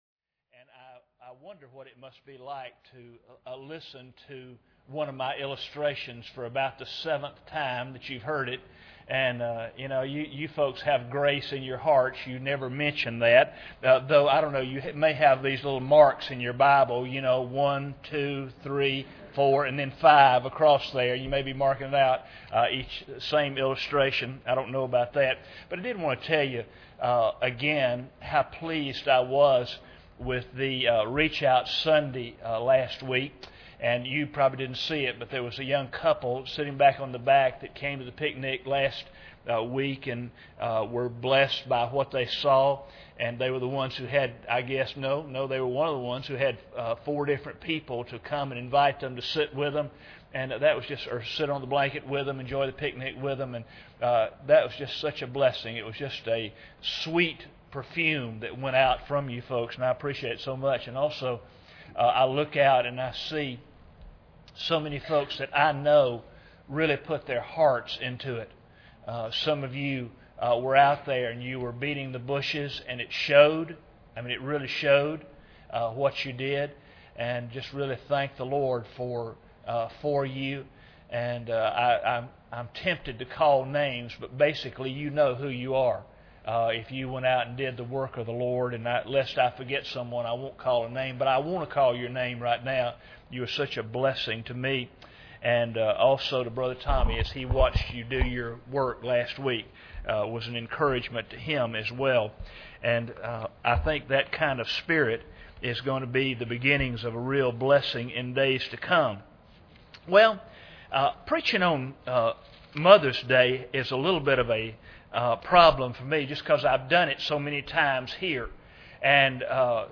Romans 14:15-19 Service Type: Sunday Evening Bible Text